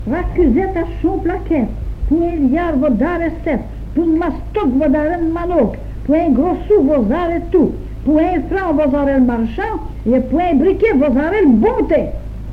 Genre : chant
Type : comptine, formulette
Province d'origine : Hainaut
Lieu d'enregistrement : Jolimont
Support : bande magnétique